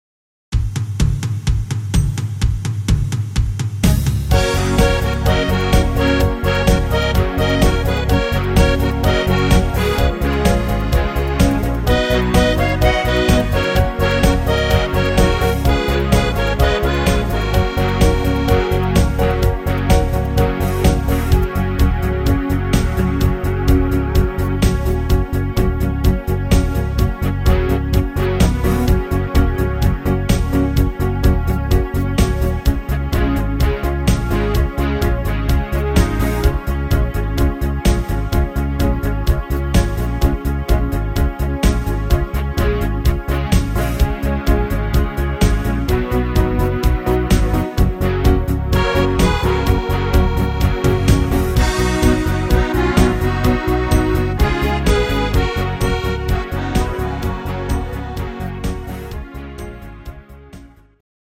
Rhythmus  Disco Rock
Art  Schlager 90er, Deutsch